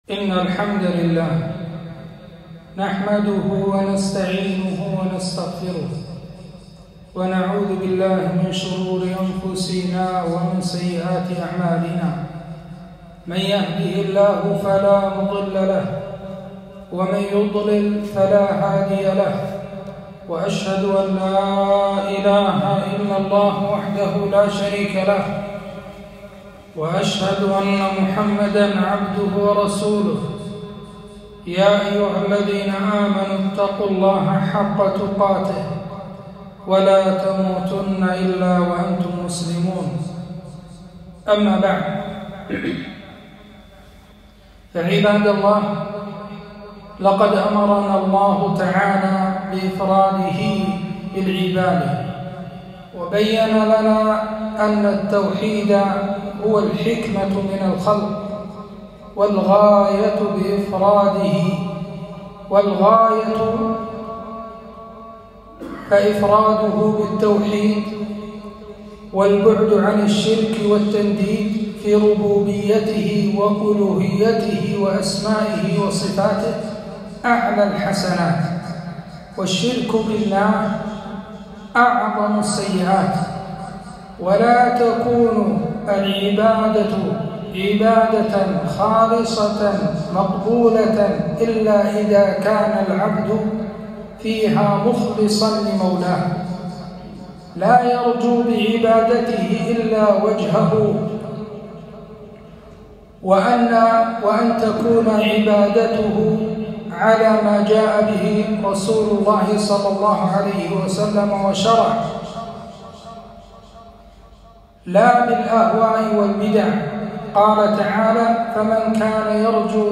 خطبة - الرياء وخطره